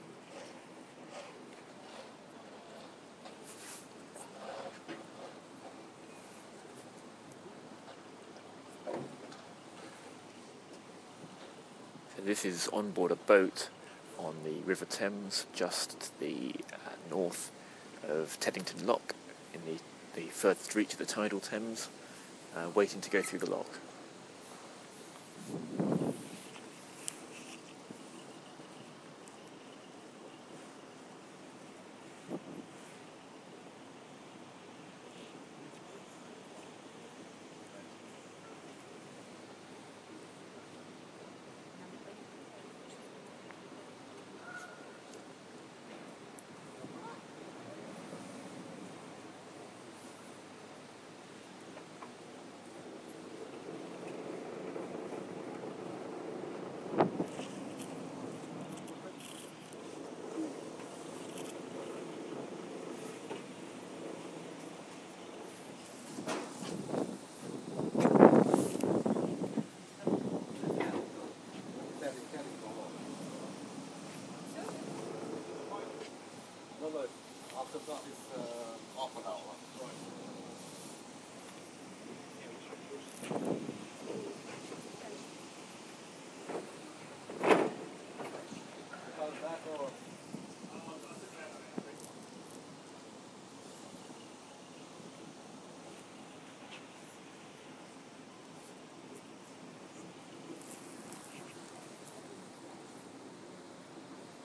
Waiting at Teddington Lock - UKSM